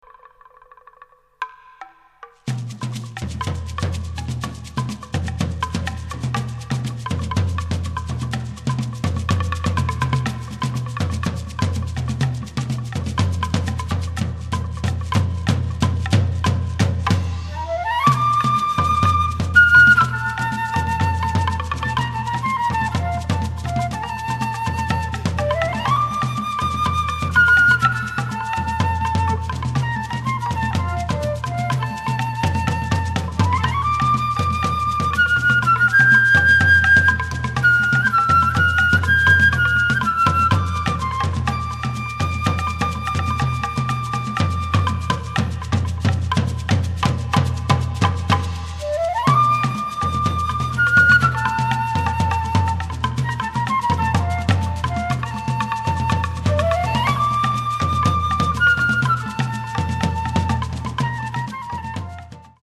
at studio Voice
バス・フルート
フルート
ハチノスギ太鼓(創作楽器)
竹琴(自作楽器)
タム